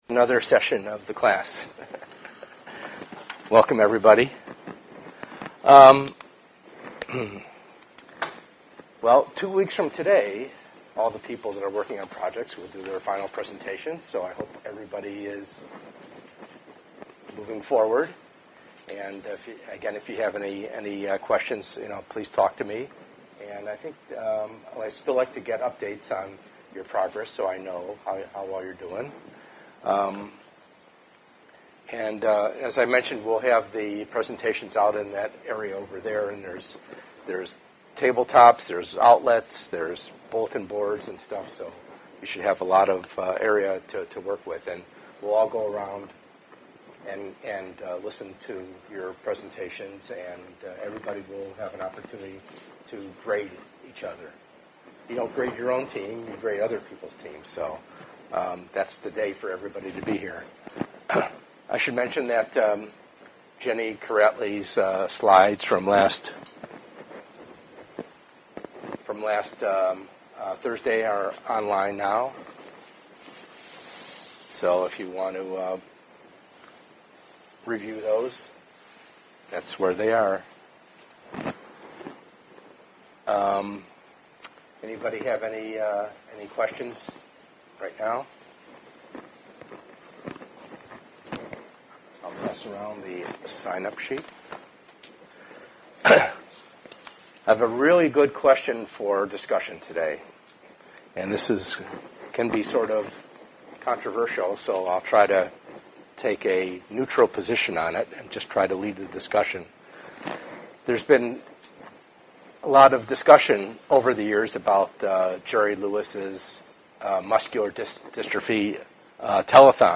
ENGR110/210: Perspectives in Assistive Technology - Lecture 8a